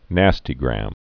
(năstē-grăm)